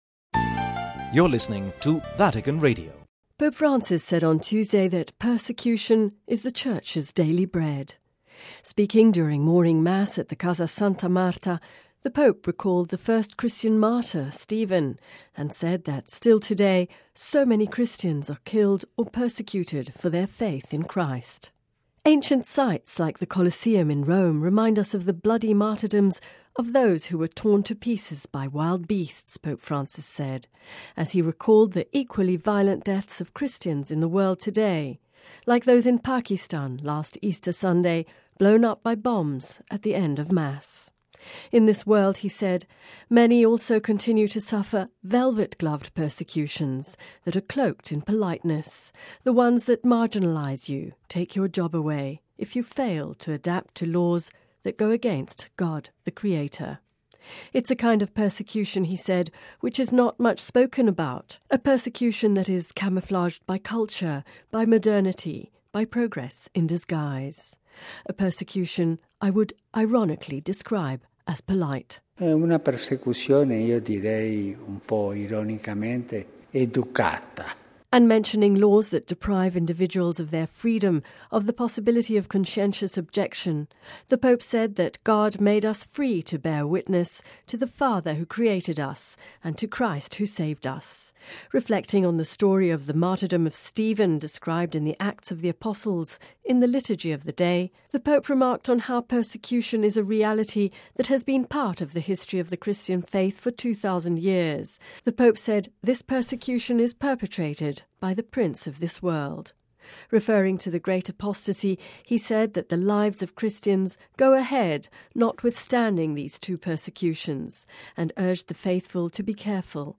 Speaking during morning Mass at the Casa Santa Marta, the Pope recalled the first Christian martyr – Stephen – and said that “still today so many Christians are killed or persecuted for their faith in Christ”.